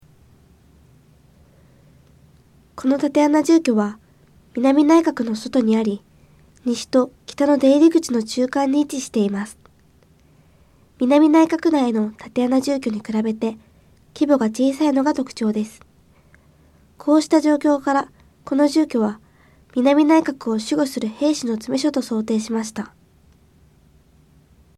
こうした状況からこの住居は南内郭を守護する兵士の詰所と想定しました。 音声ガイド 前のページ 次のページ ケータイガイドトップへ (C)YOSHINOGARI HISTORICAL PARK